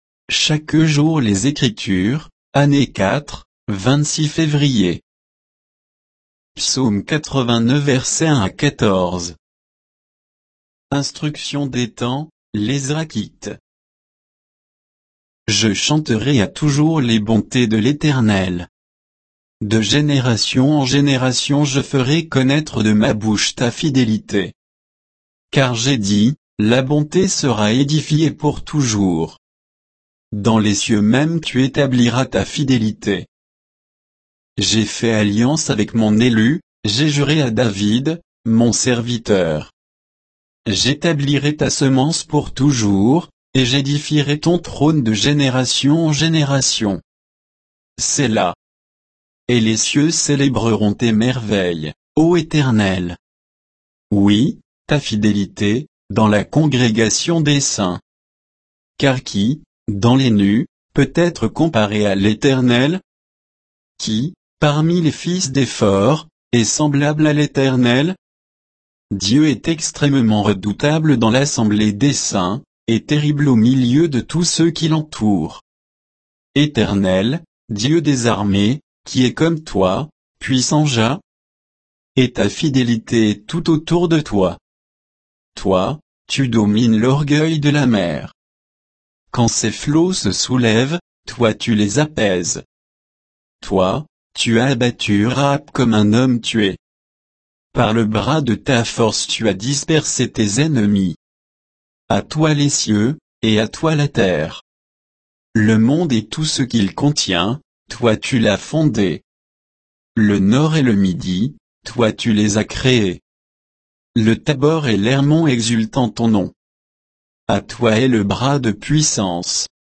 Méditation quoditienne de Chaque jour les Écritures sur Psaume 89